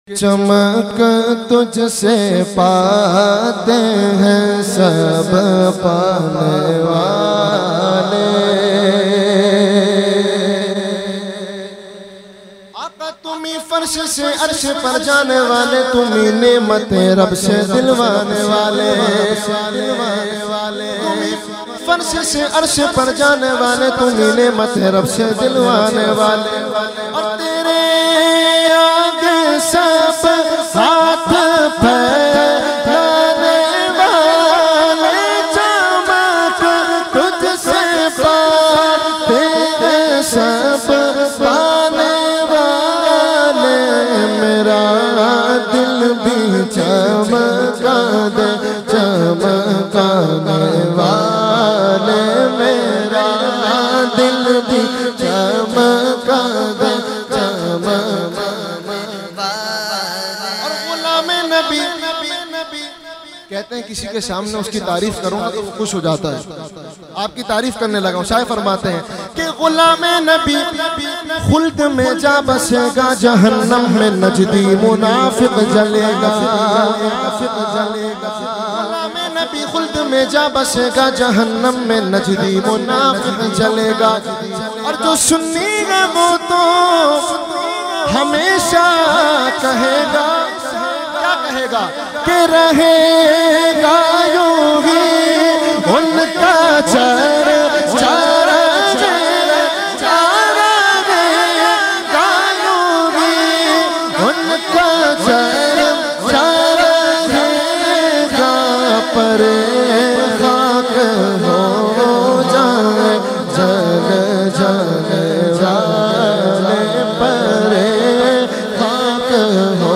Category : Naat | Language : UrduEvent : Muharram 2020